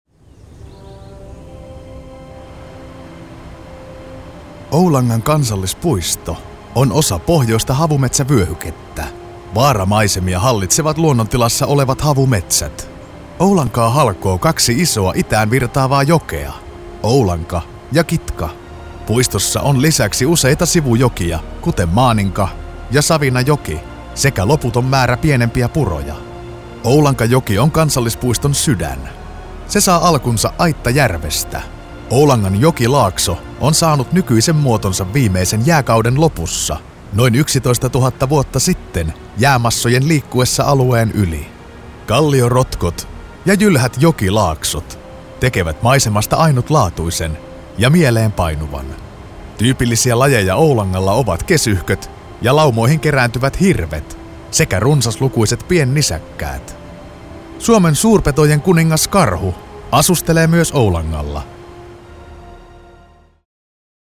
Native finnish professional voiceover artist with a warm versatile voice for all kind of voiceover work.
Sprechprobe: Sonstiges (Muttersprache):
I record using a very highend recording gear and signal chain.